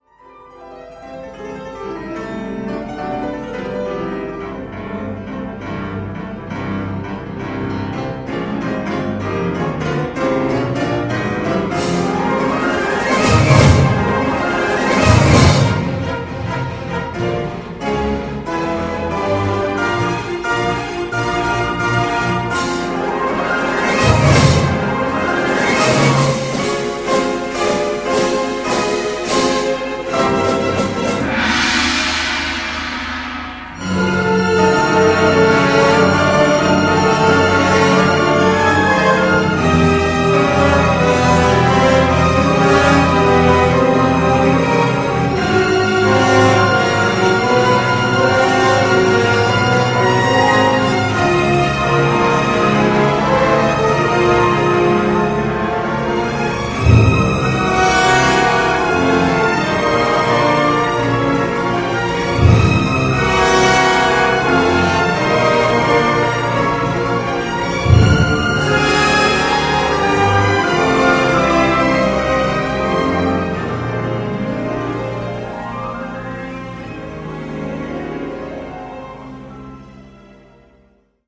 Gershwin_-_Concerto_in_F.mp3